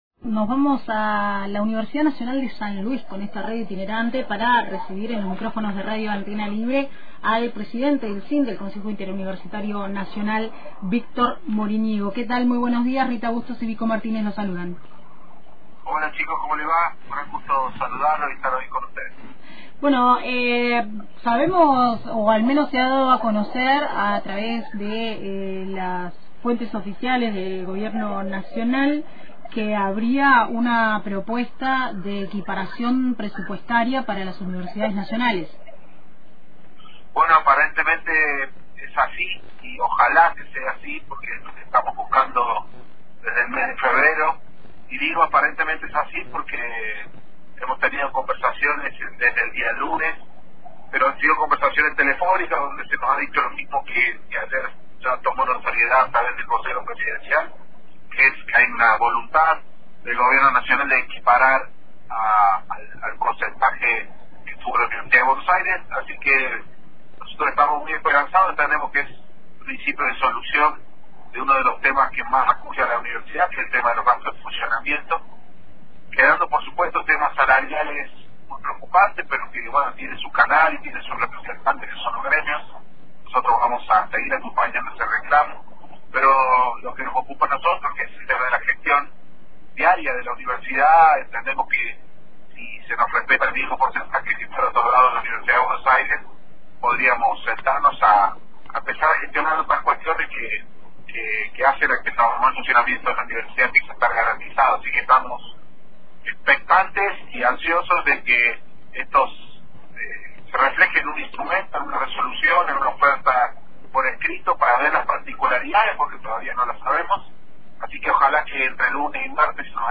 Escuchá la entrevista completa a Víctor Moriñigo acá: